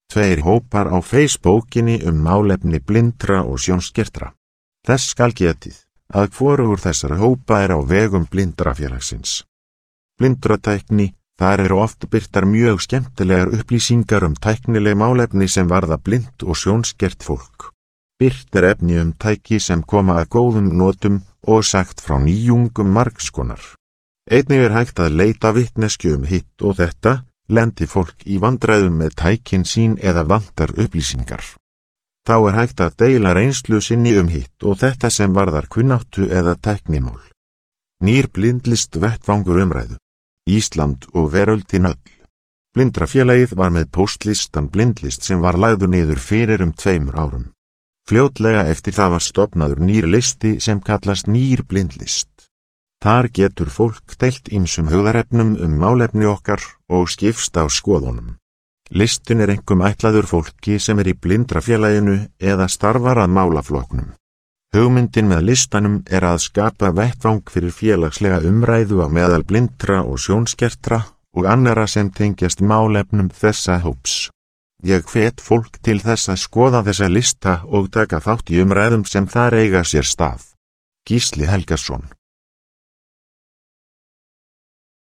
Hljóðritað hjá Hljóðbók slf í febúar 2019.